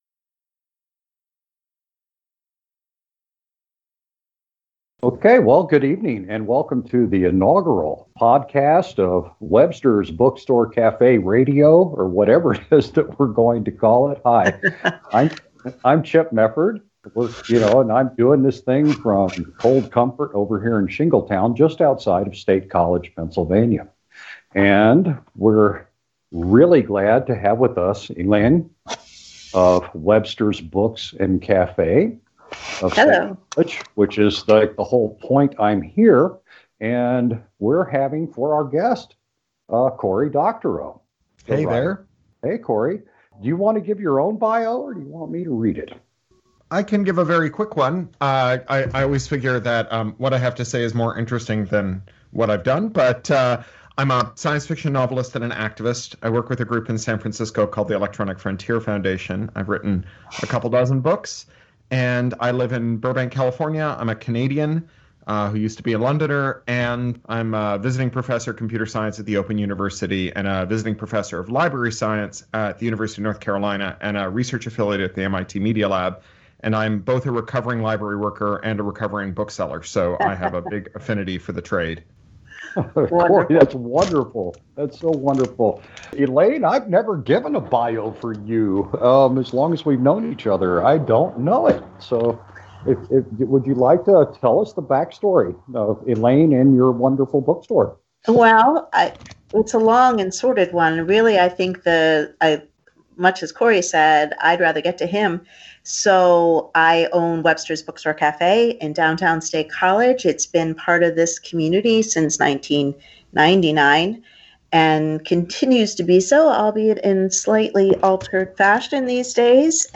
It was a softball interview.